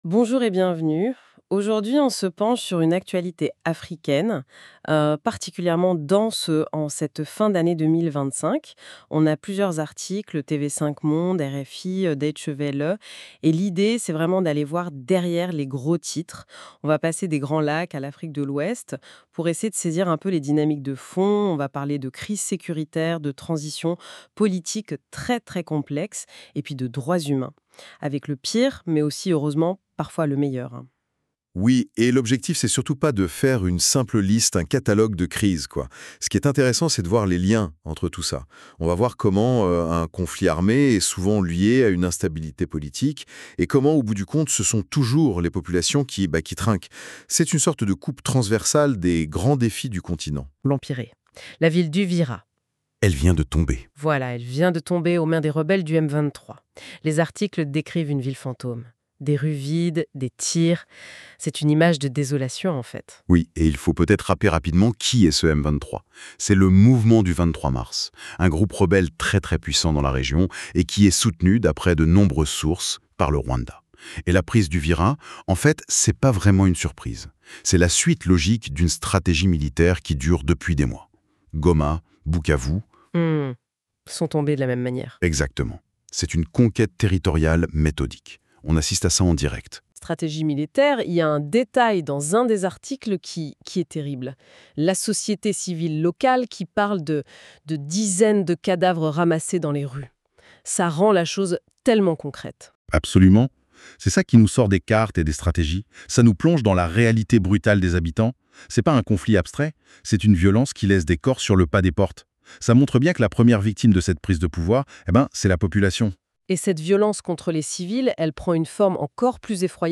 Émission en direct